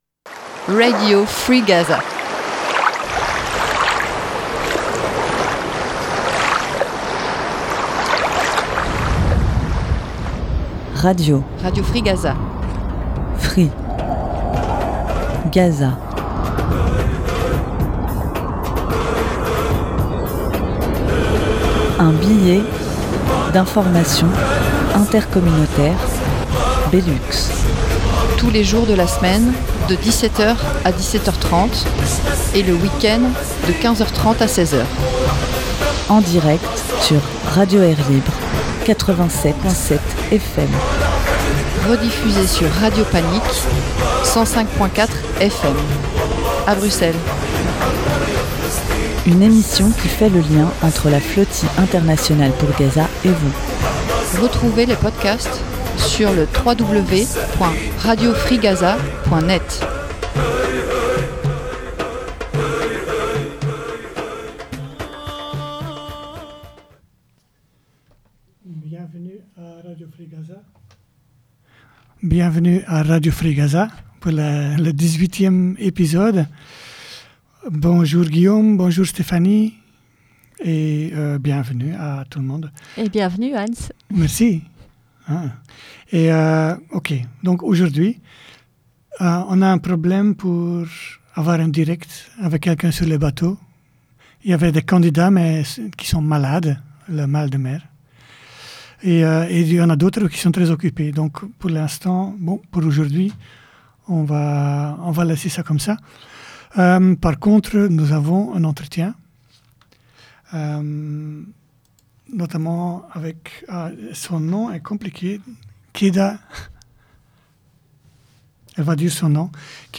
Nos interlocuteurs sur les bateaux avaient le mal de mer, ou étaient occupées avec la navigation, donc nous avons du passer directement à l’entretien.